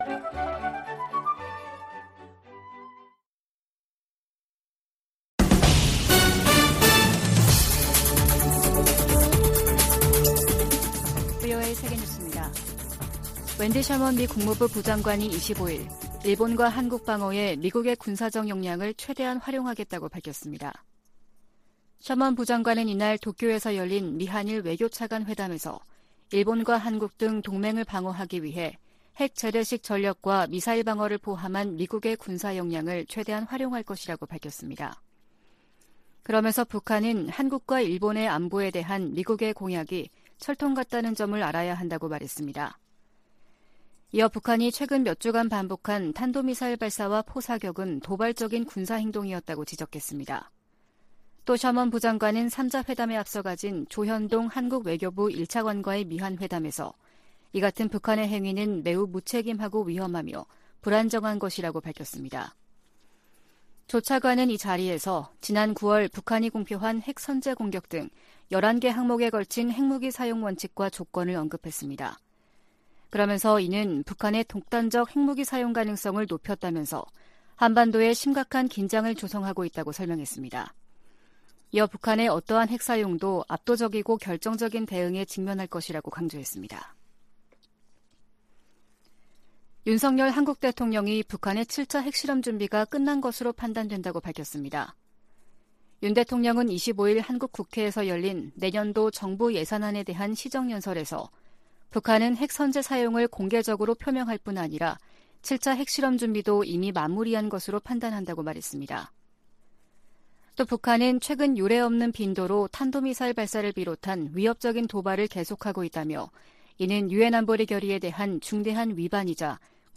VOA 한국어 아침 뉴스 프로그램 '워싱턴 뉴스 광장' 2022년 10월 26일 방송입니다. 백악관은 북한이 도발을 계속하고 있는데 우려를 나타내면서, 조건없이 대화할 의향도 재확인했습니다. 국무부는 북한이 7차 핵실험을 준비 중이라는 기존의 평가를 확인하며 동맹·파트너와 만일의 사태에 대비하고 있다고 밝혔습니다. 미국의 전문가들은 시진핑 3기 중국이 북한에 더 밀착하며, 핵실험에 눈 감고 대북제재에 협조 안할 것으로 내다봤습니다.